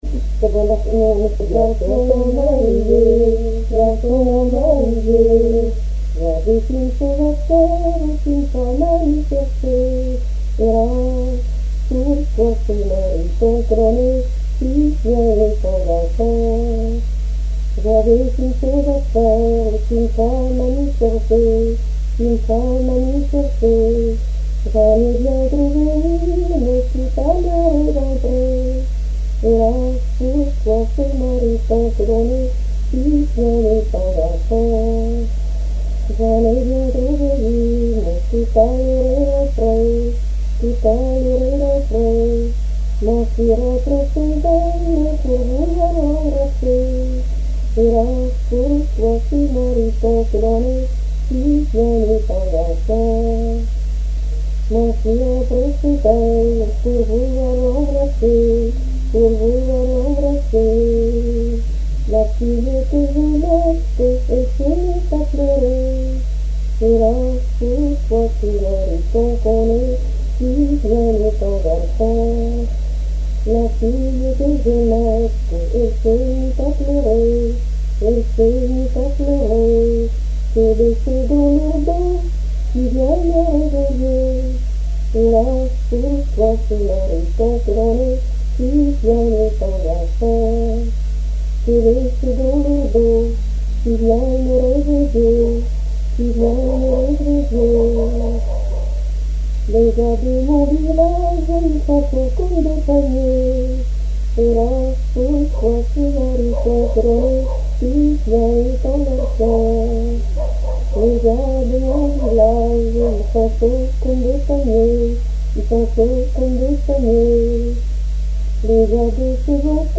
Mémoires et Patrimoines vivants - RaddO est une base de données d'archives iconographiques et sonores.
Genre laisse
répertoire de chansons
Pièce musicale inédite